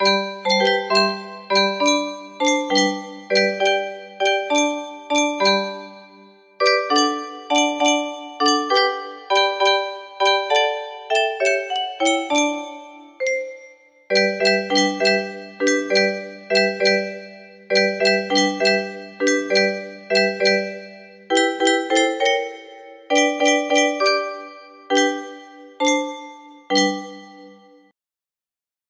Jesus is all the World to Me music box melody
Grand Illusions 30 (F scale)